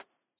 snd_gem1.ogg